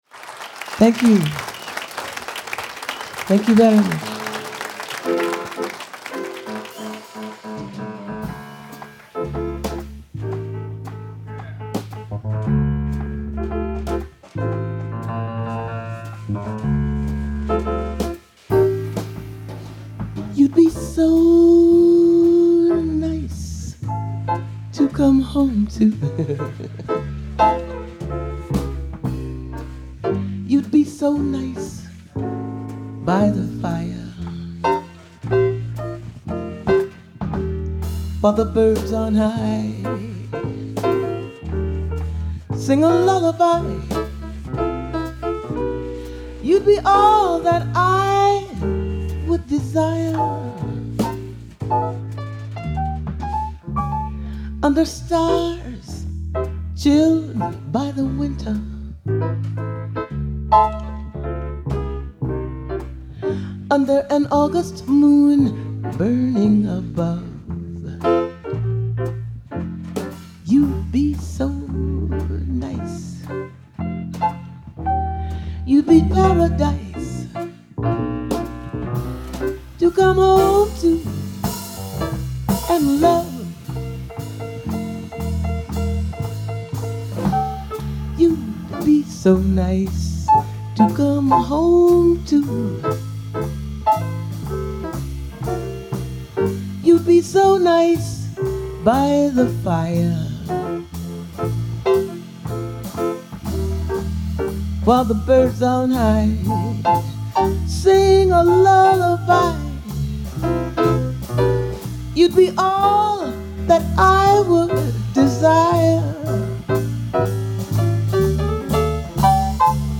Жанр: Jazz Vocals.